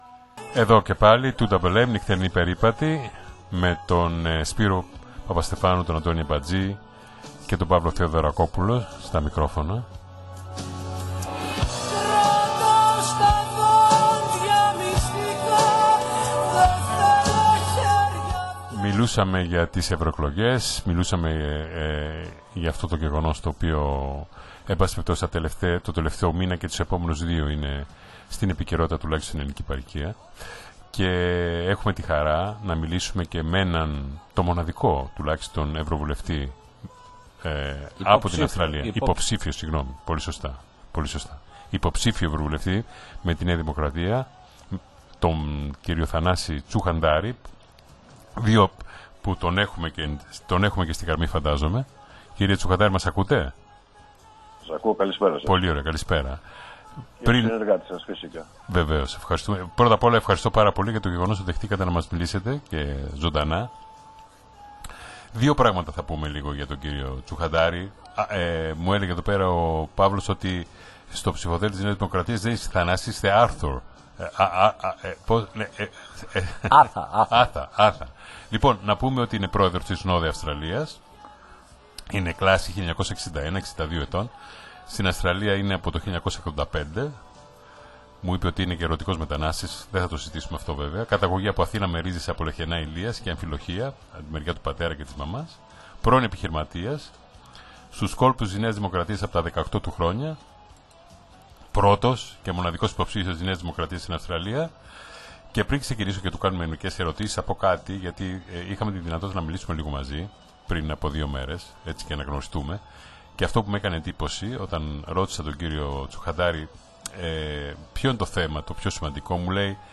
ζωντανή συνέντευξη